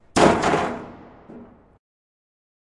废弃的工厂 金属后世界末日的回声 " 砰砰的回声 1b
描述：记录在都柏林的一家废弃工厂。
Tag: 工业 工厂 金属 崩溃 噪声